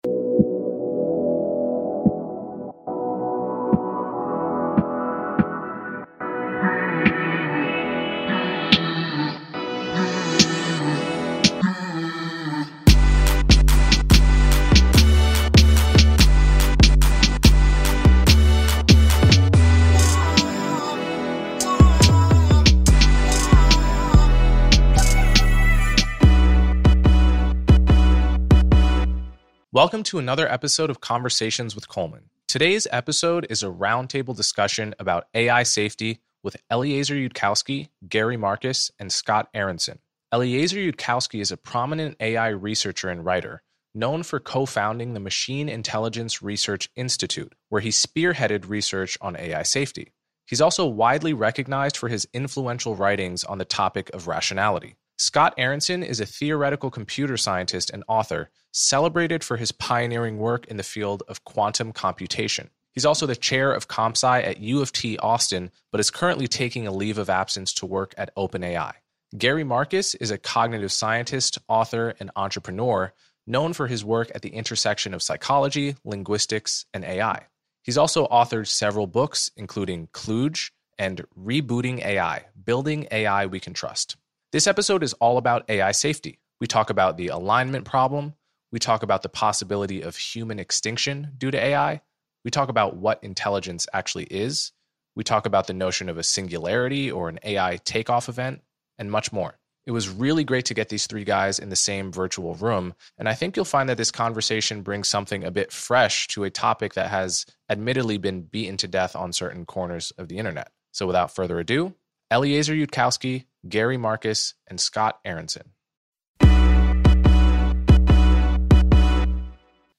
Today's episode is a roundtable discussion about AI safety with Eliezer Yudkowsky, Gary Marcus, and Scott Aaronson.